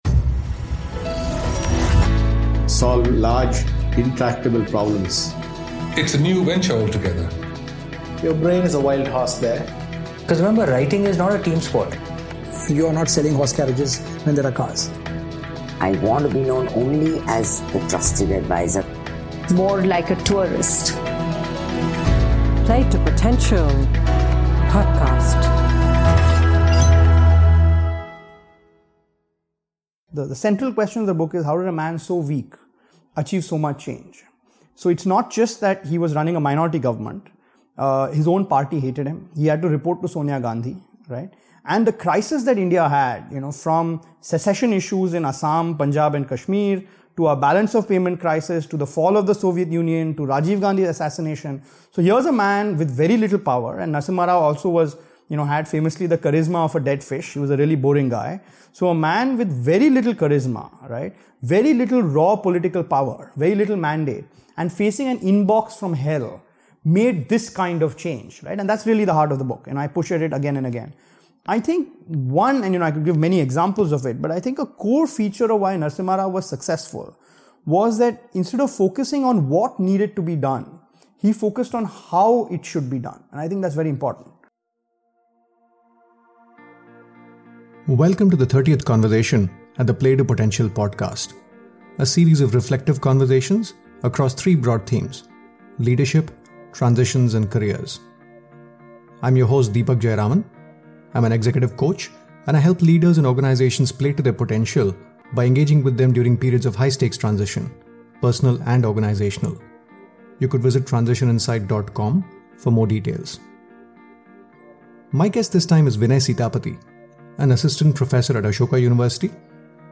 In our conversation, we spoke about P.V. Narasimha Rao’s unfettered curiosity across disciplines, his resilience that saw him manage the vicissitudes in his political career, his intellectual and implementation agility, his ability to reflect and introspect, judgment around playing like a Lion, Fox or a Mouse depending on the context, capacity to reinvent himself across various shifts and turns in his journey, how he built teams and added value as a leader, how not making a decision was a decision in itself and driving change through a complex system where there is visible short-term pain but long-term gain.